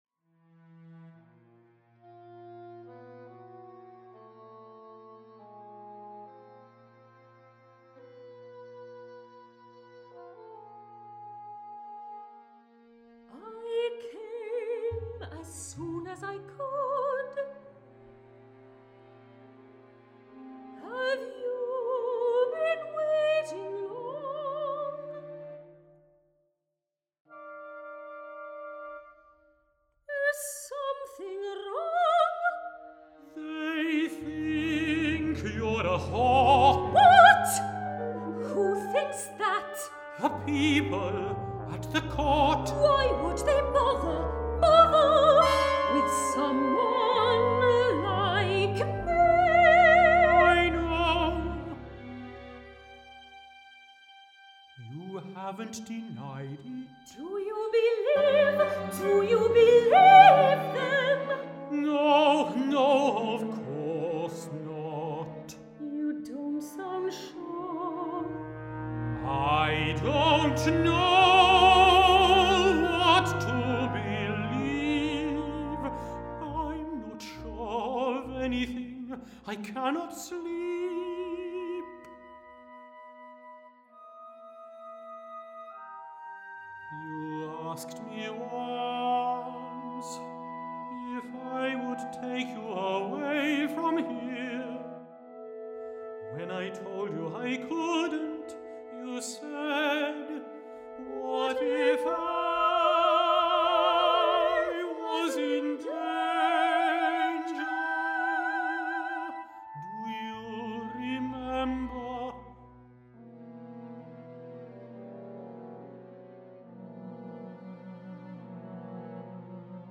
soprano
tenor